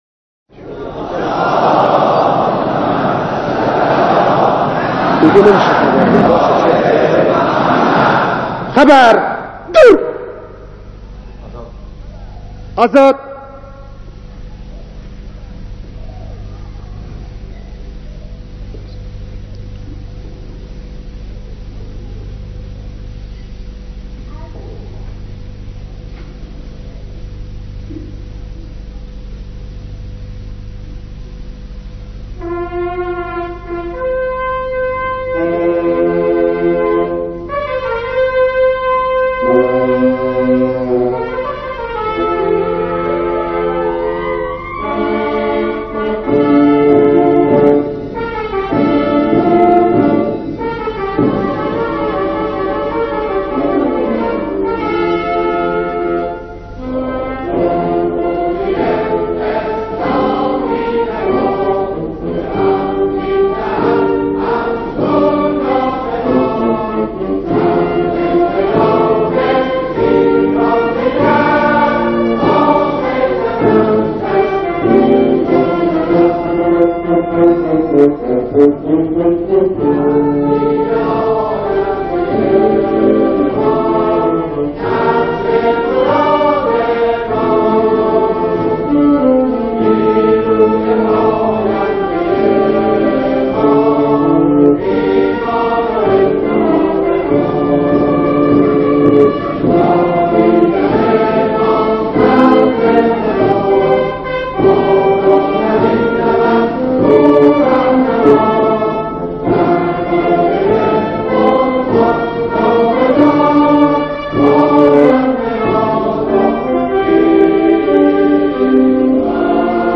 بیانات رهبر انقلاب در دیدار فرمانده و جمع کثیری از پرسنل نیروی هوایی ارتش